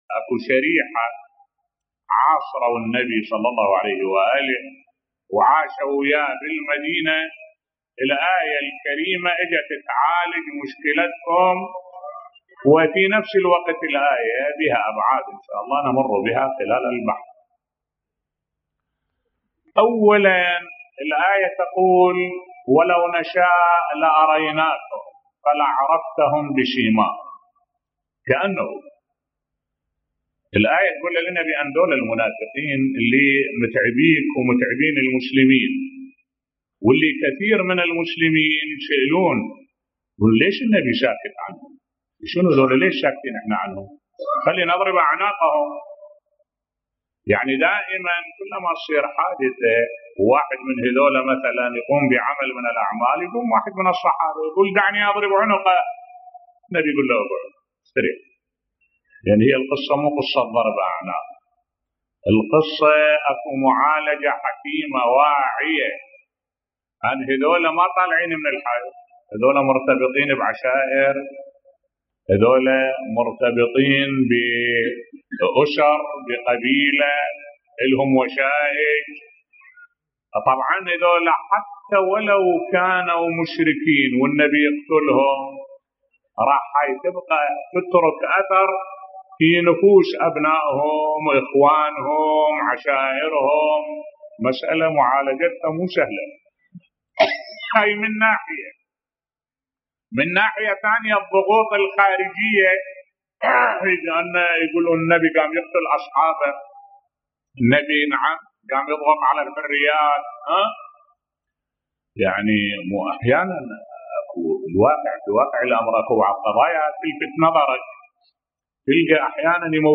ملف صوتی لماذا رسول الله لم يقتل المنافقين وهو يعلم بهم بصوت الشيخ الدكتور أحمد الوائلي